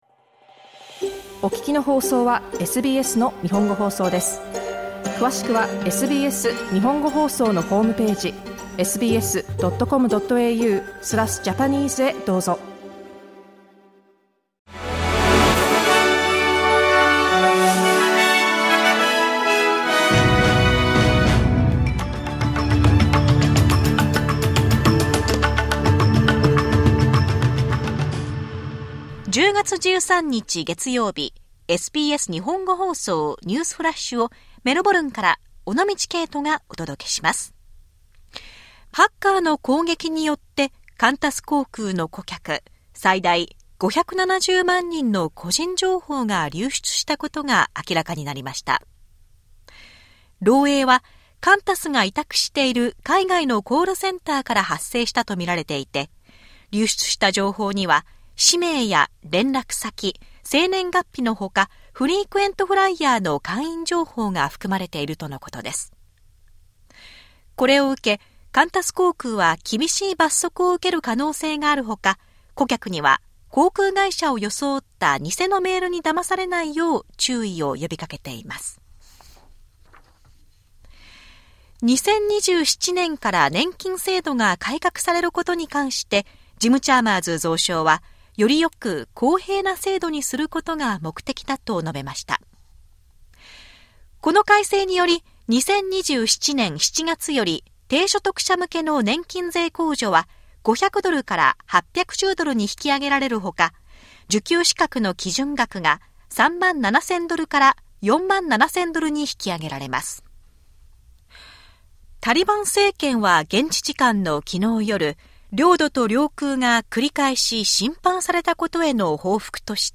SBS日本語放送ニュースフラッシュ 10月13日 月曜日